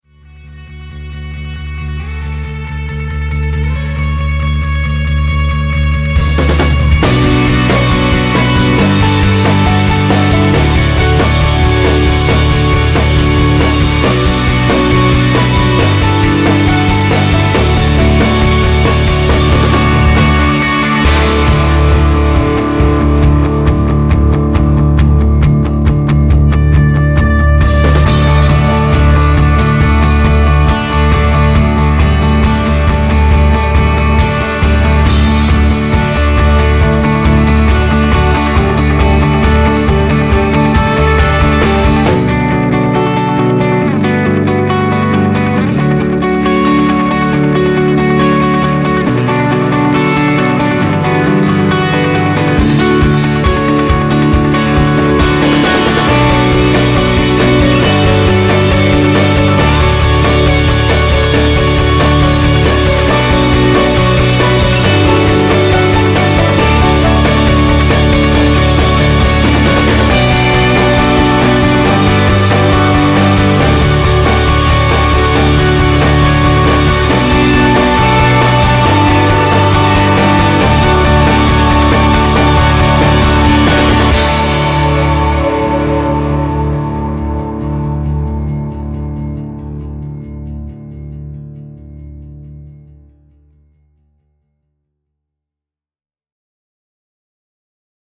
INDIE ROCK - INSTRUMENTALS